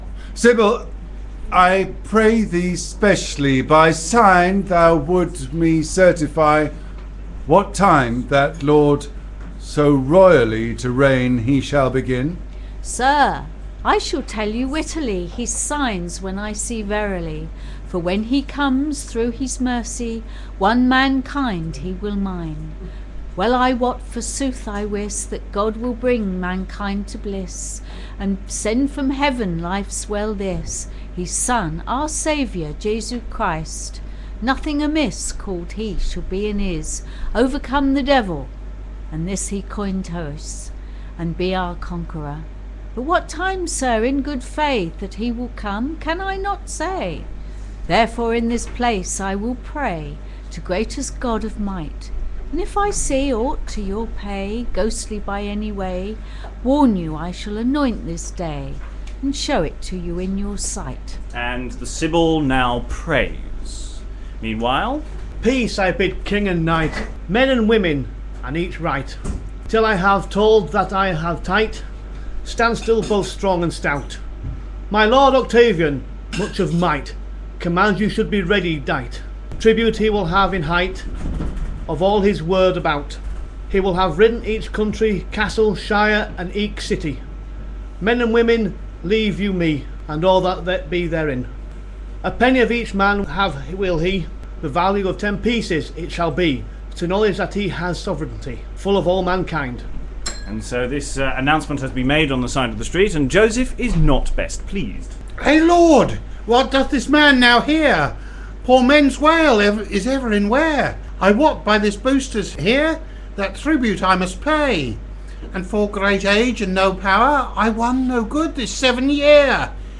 Facebook Twitter Headliner Embed Embed Code See more options Exploring the Chester Mystery Plays is a series of live streamed events where the Chester plays are taken apart with readers and commentary. Rough round the edges, edited versions of these events are now being posted online. This post continues our look at play 6, The Nativity, where there's an addition of two midwives to the traditional story.